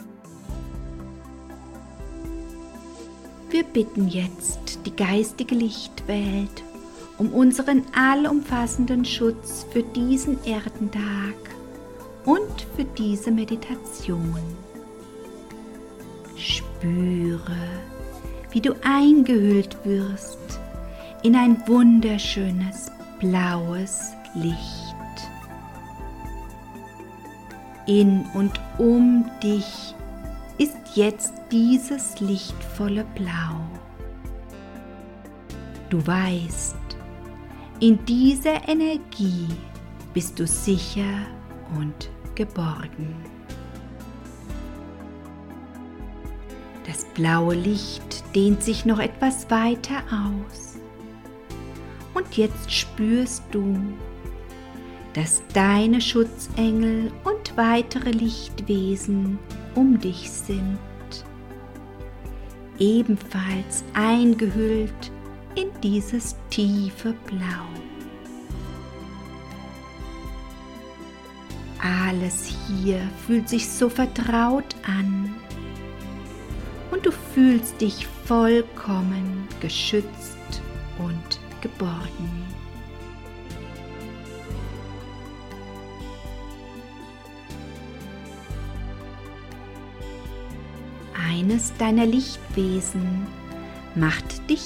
Eine geführte Meditation speziell um den Lebensplan wieder zu aktivieren und zu intensivieren
Hörprobe: Eine geführte Meditation meinen Lebensplan wieder inte  (2 MB)